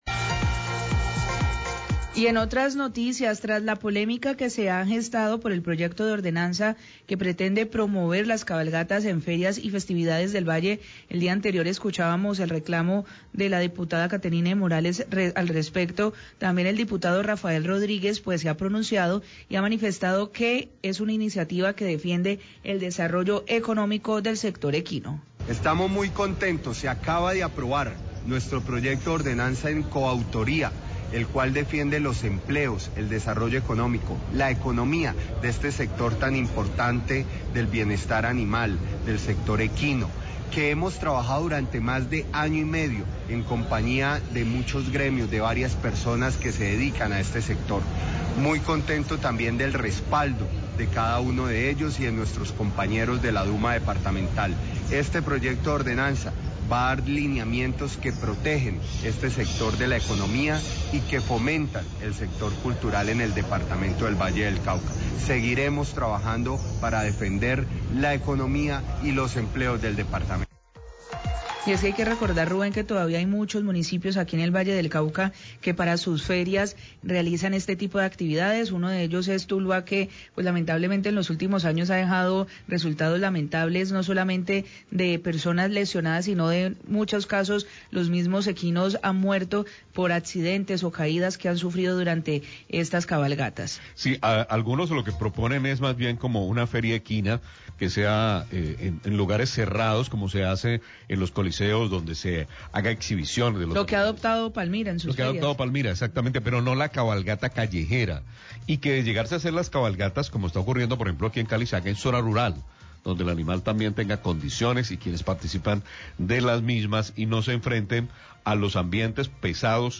Radio
Tras la polémica por la aprobación del proyecto de ordenanza que busca promover las cabalgatas en ferias del Valle, el Diputado Rafael Rodríguez se pronunció defendiendo el proyecto diciendo que es una iniciativa que defiende el desarrollo económico del sector equino.